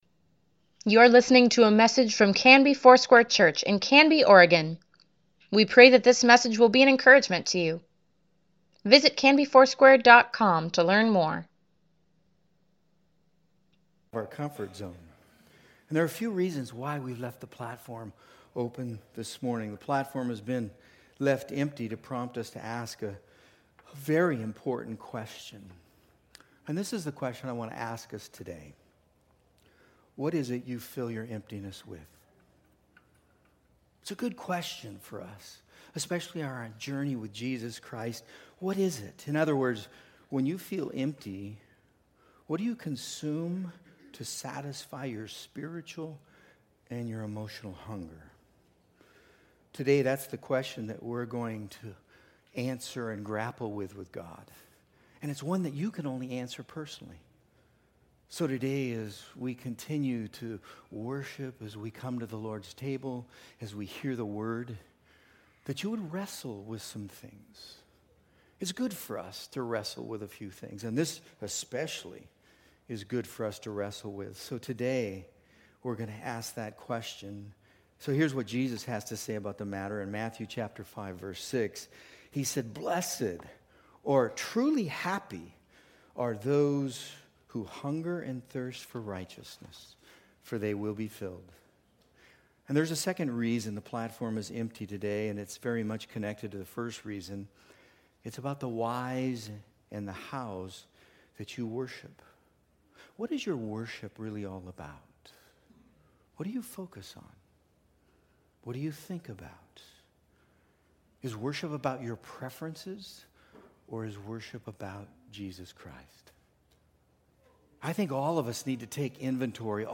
Weekly Email Water Baptism Prayer Events Sermons Give Care for Carus What About Giving - Part 2 November 3, 2019